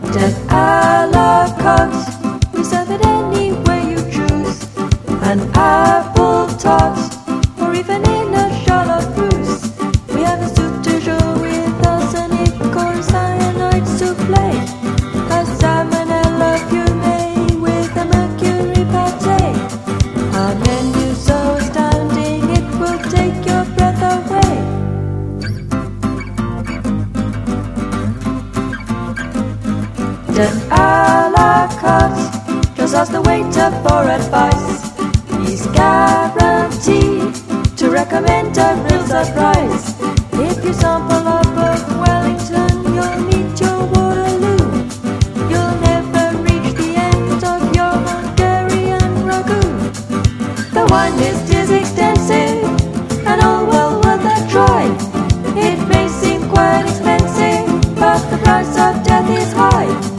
NEO-ACO/GUITAR POP
キュート・ガーリィ・アコースティックな永遠の名曲